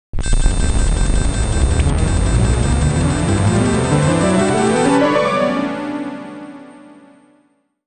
UI_SFX_Pack_61_39.wav